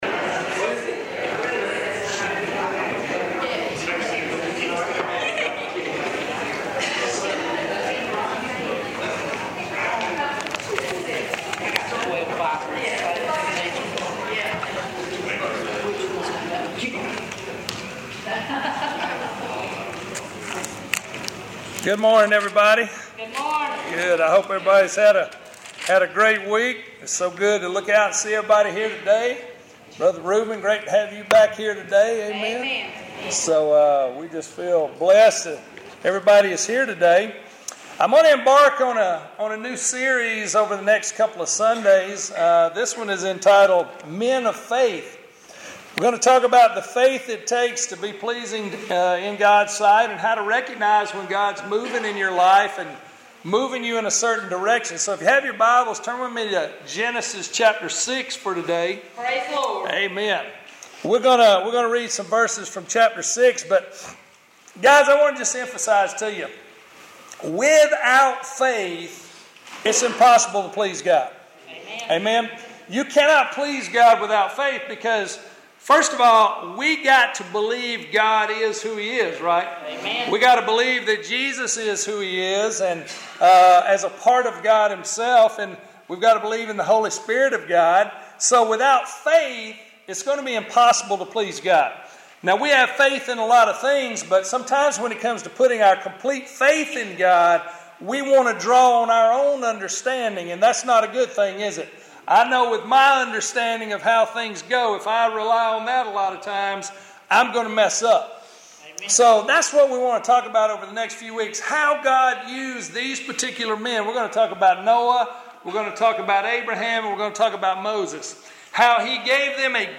Bethesda Baptist Church Sermons Page